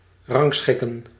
Ääntäminen
UK : IPA : /sɔːt/ US : IPA : [sɔːt] US : IPA : /sɔɹt/